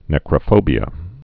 (nĕkrə-fōbē-ə)